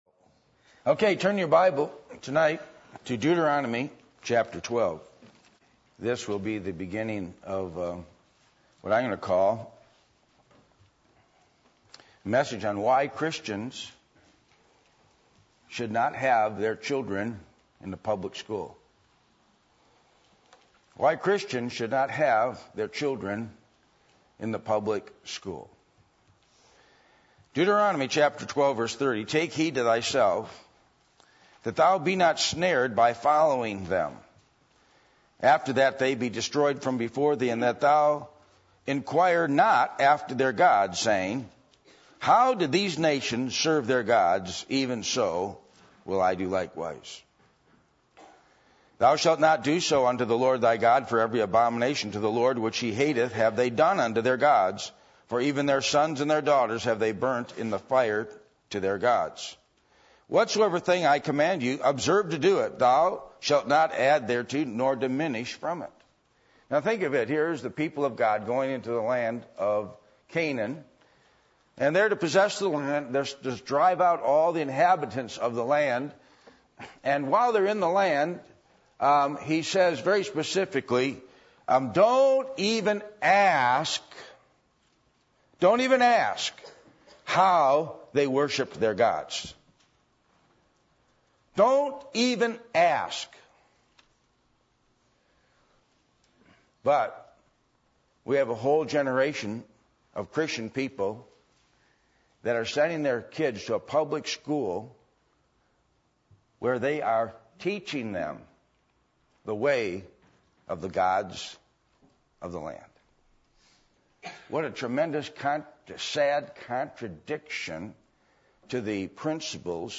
Passage: Deuteronomy 12:30-32 Service Type: Sunday Evening